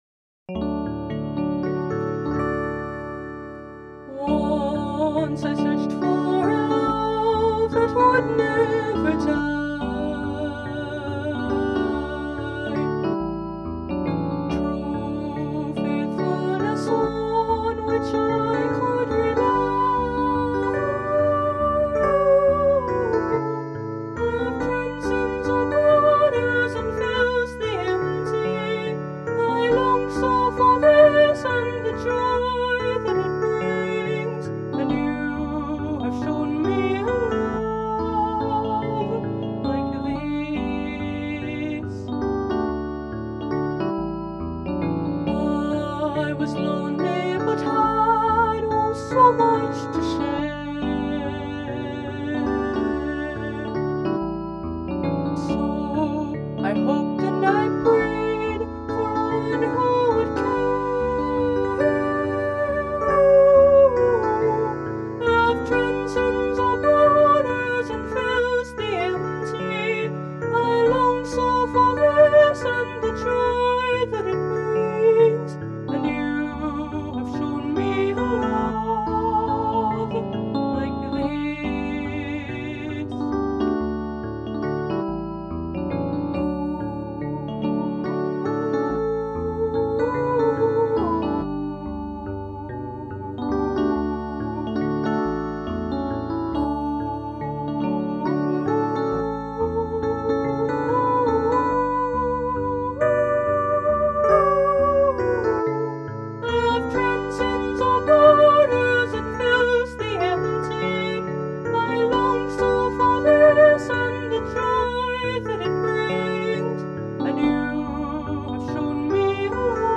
Original Songs
Not every song I wrote on piano was in 3/4 or waltz time, even though all of these are. 🙂